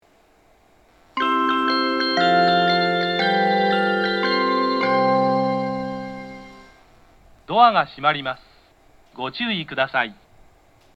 発車メロディー
一度扱えばフルコーラス鳴ります。
スピーカーが小丸VOSSになって音質が格段に良くなりました。